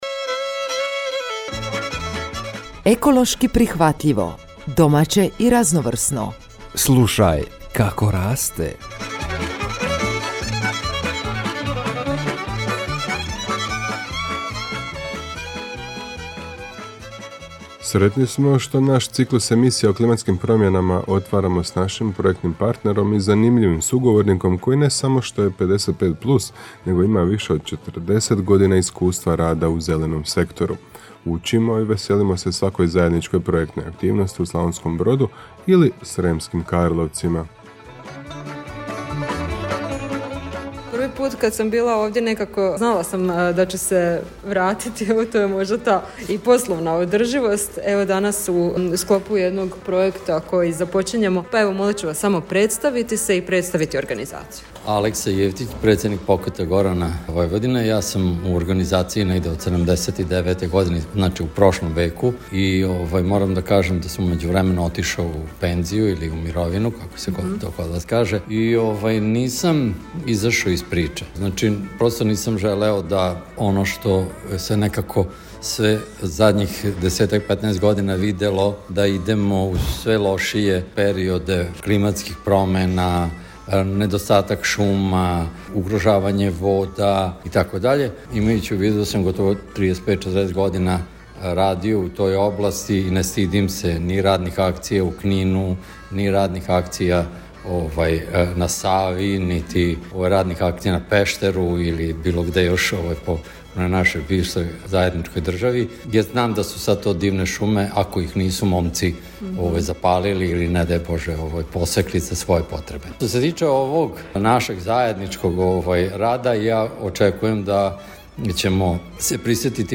Ovaj edukativni sadržaj objavljujemo u obliku radijske emisije koju možete uvijek ponovno poslušati.